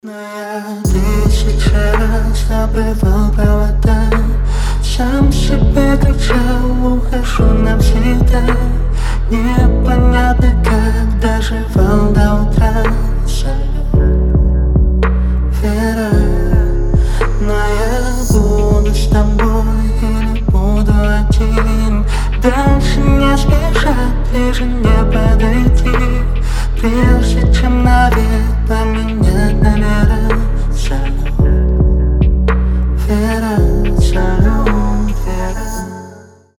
• Качество: 320, Stereo
мужской голос
спокойные
Cover
нежные